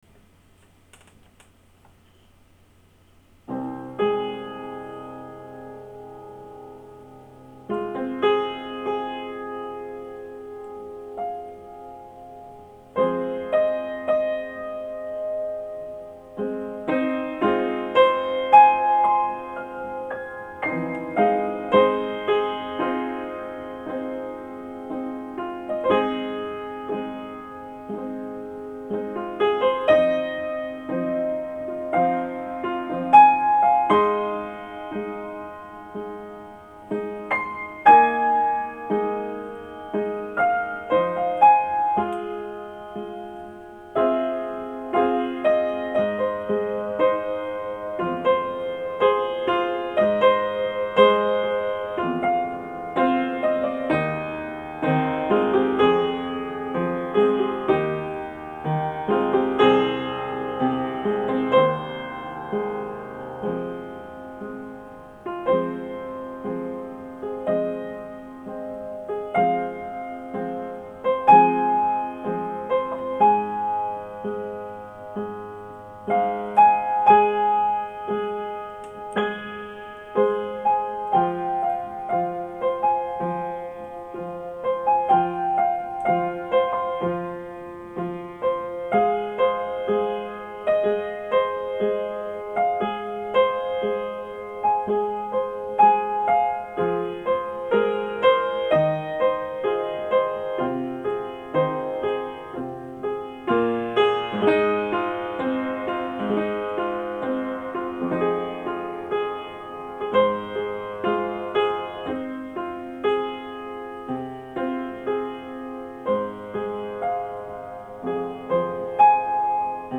A late-night improvisation on the old upright Beckwith back in April of 2010; this was the sort of thing I got up to when the power went out or there was nothing else to do (there was often one, the other, or both of these in effect in those days).
I’m not sure that this had any sort of theme or intent; it just rolled off my fingertips, errors and all, on the spot.